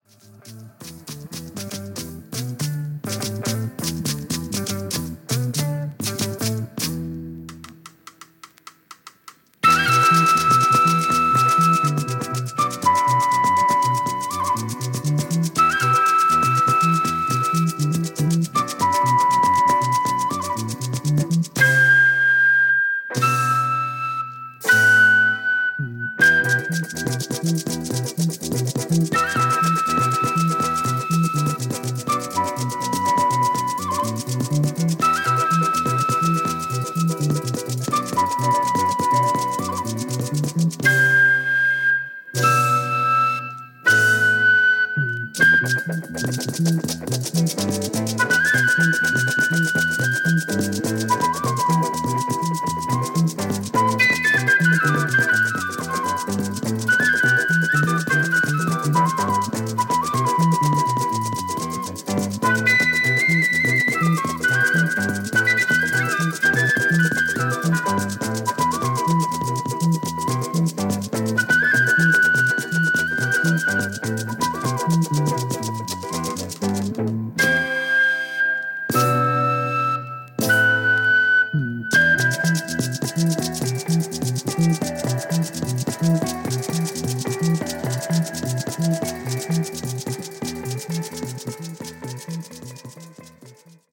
ガーナ・アフロバンド